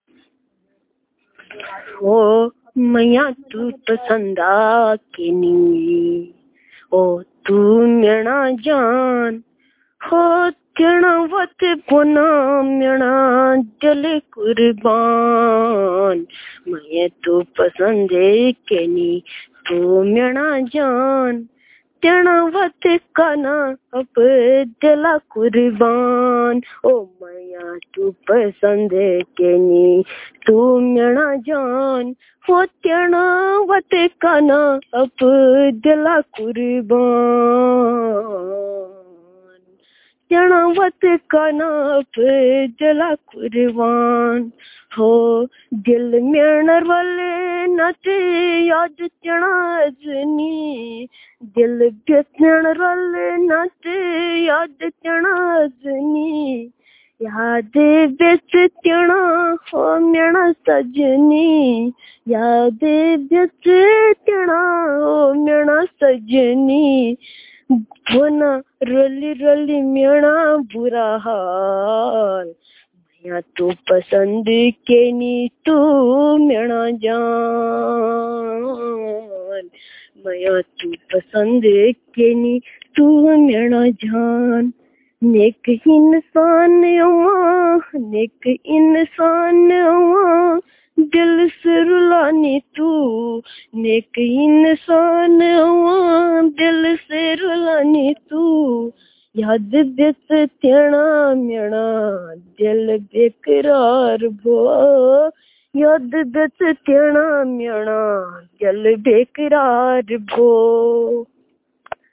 Performance of folk songs